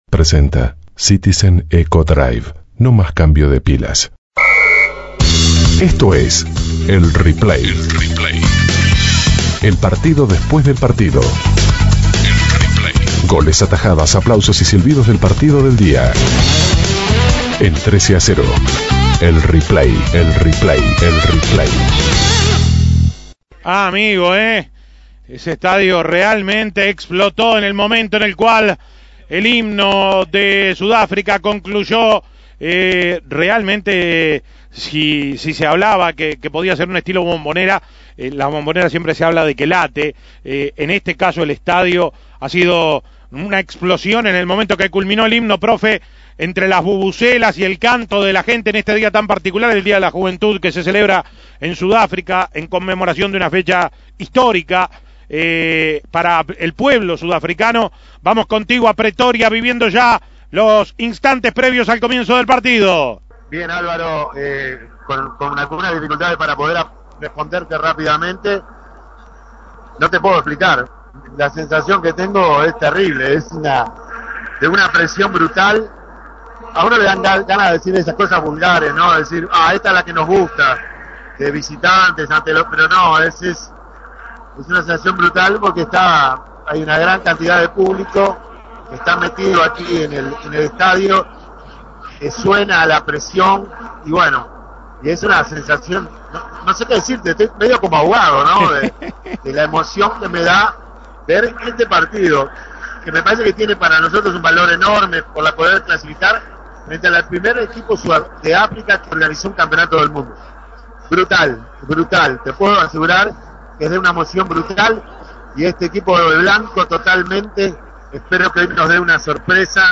Goles y comentarios Escuche el Replay de la victoria de Uruguay ante Sudáfrica Imprimir A- A A+ Uruguay le ganó a Sudáfrica 3-0 y quedó a un paso de los octavos de final.